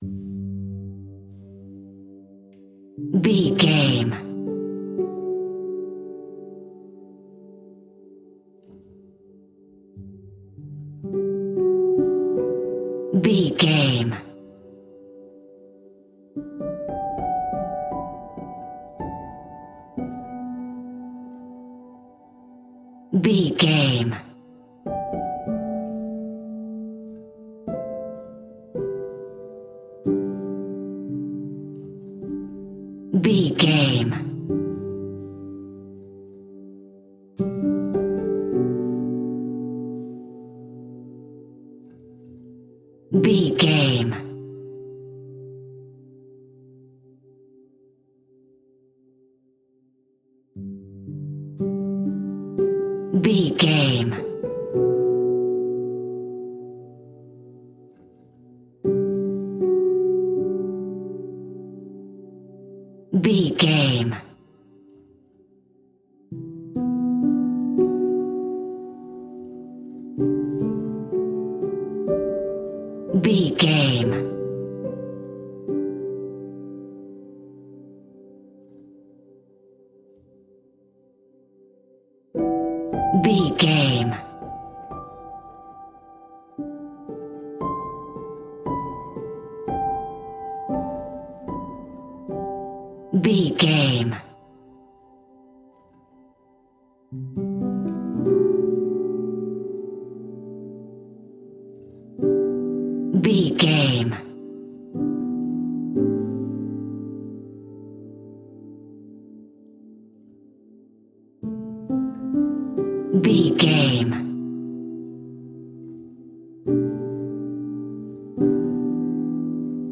Ionian/Major
Slow
relaxed
tranquil
synthesiser
drum machine